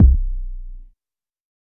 • 00's Boom Kick Drum C Key 158.wav
Royality free kick drum single hit tuned to the C note. Loudest frequency: 90Hz
00s-boom-kick-drum-c-key-158-ZHV.wav